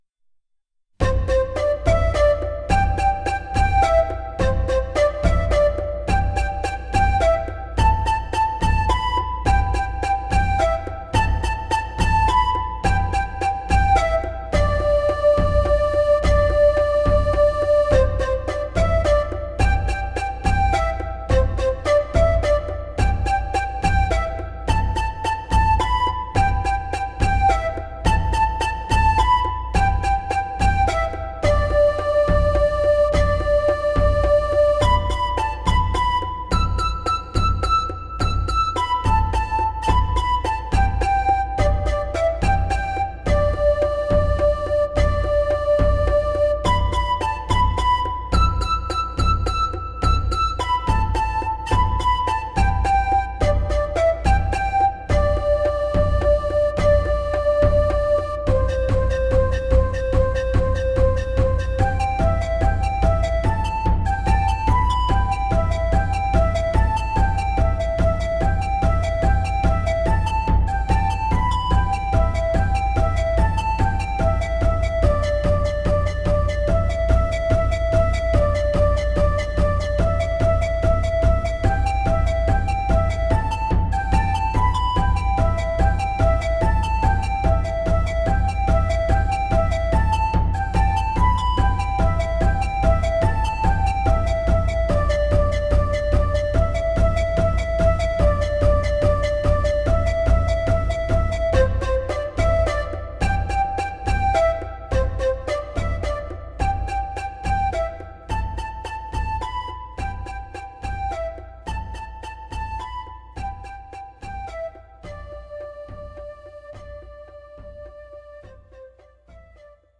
Recorded digitally, using a M-Audio FireWire Audiophile.